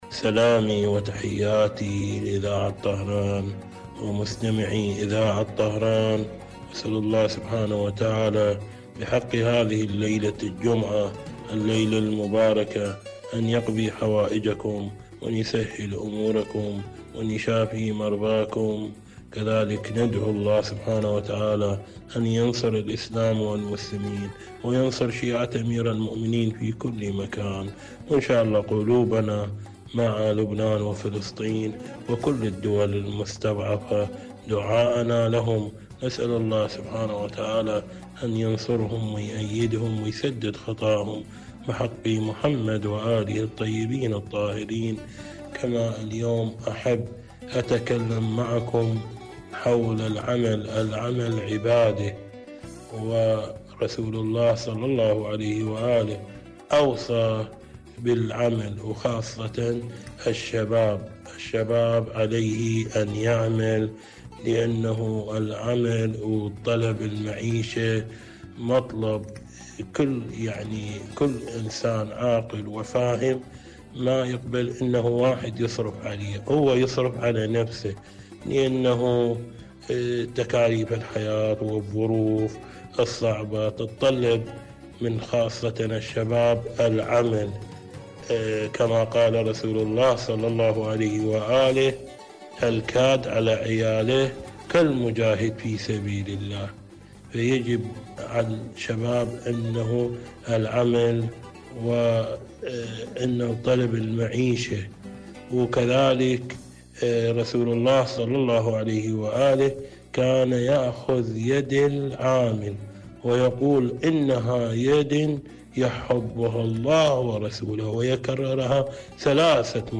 إذاعة طهران- المنتدى الإذاعي